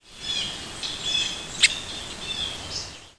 Red-eyed Vireo Vireo olivaceus
Has been documented to give a soft "cht" note in short diurnal flights.
Single call from rapid series. Blue Jay calling in the background.
Similar species Warbling Vireo gives a similar but higher-pitched call.